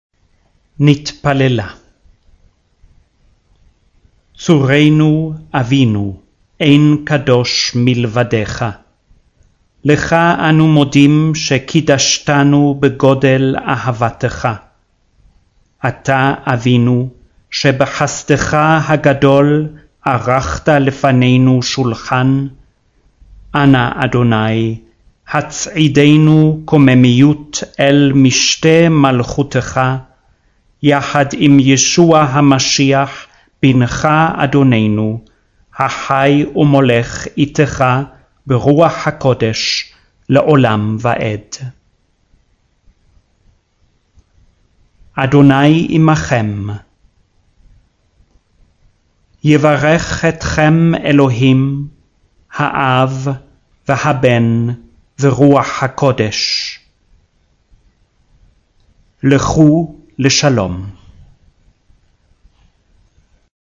il testo della liturgia cattolica romana letta lentamente in ebraico